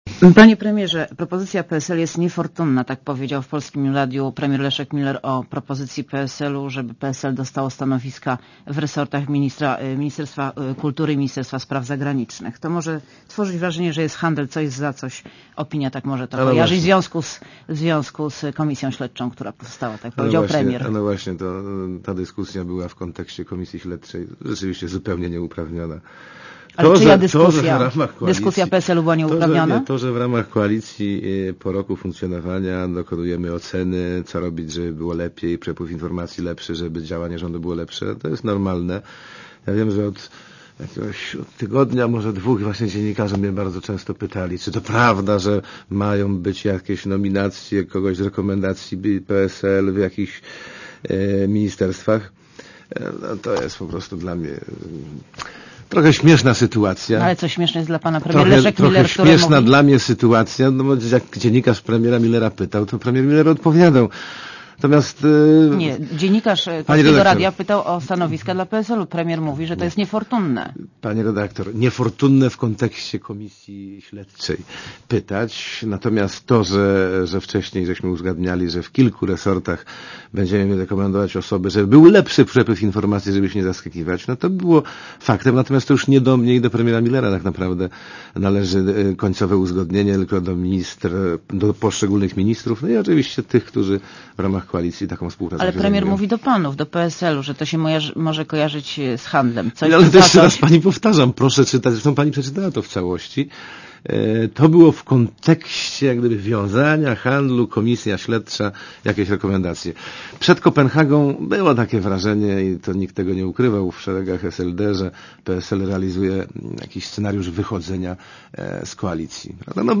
Monika Olejnik rozmawia z wicepremierem Jarosławem Kalinowskim